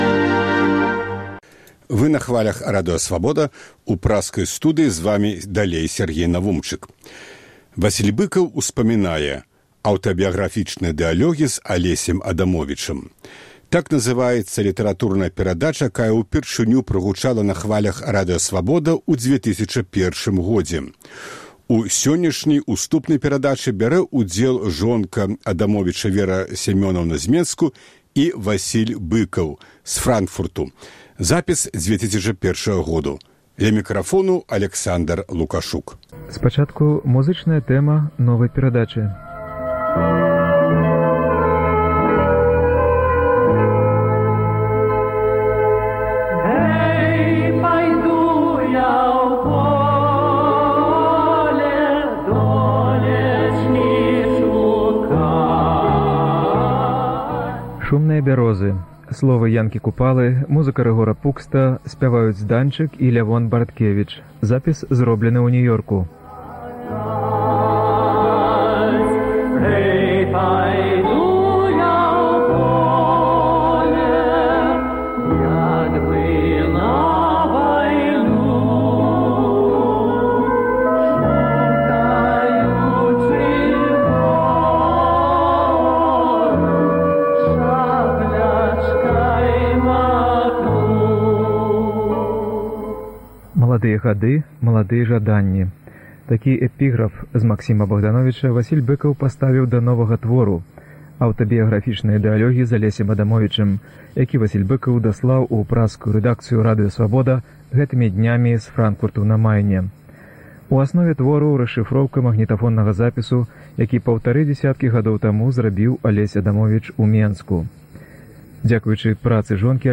Так называецца літаратурная перадача, якая ўпершыню прагучала на хвалях Радыё Свабода ў 2001 годзе.